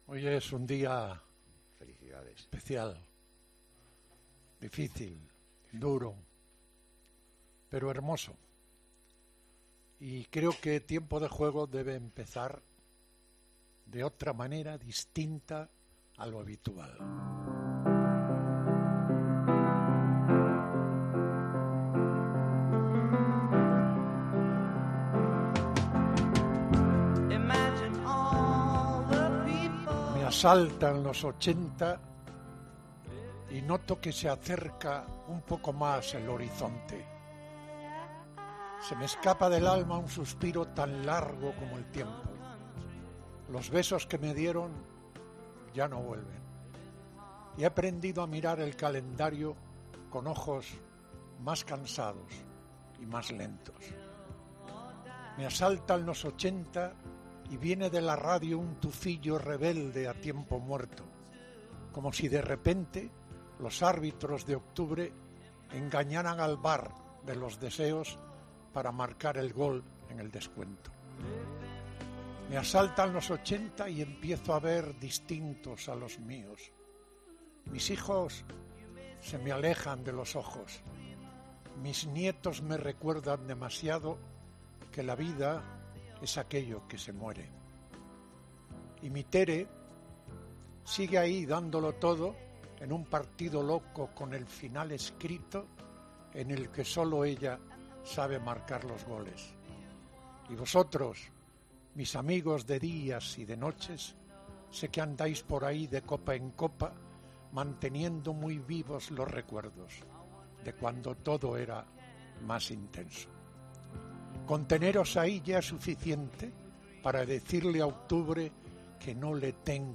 Al comienzo del programa, Pepe ha emocionado a todos los oyentes con un emotivo discurso de celebración.
Paco González y Pepe Domingo Castaño, en el estudio de 'Tiempo de Juego'